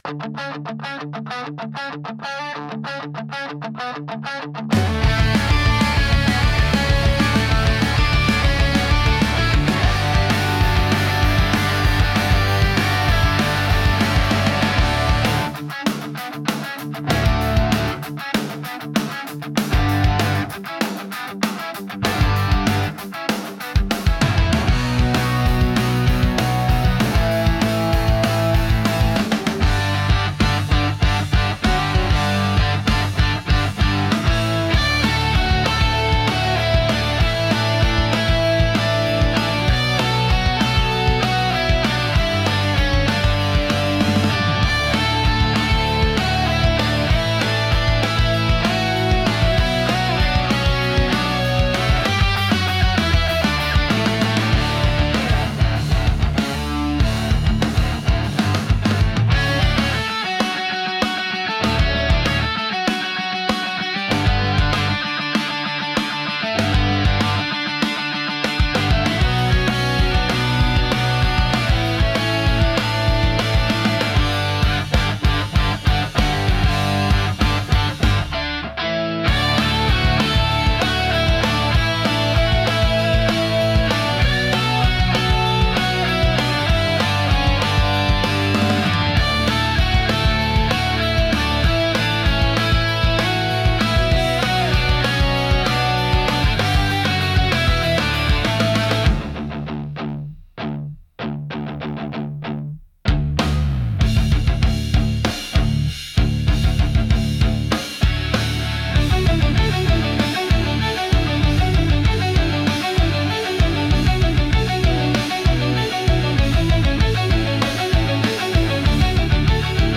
Genre: Pop Punk Mood: Upbeat Editor's Choice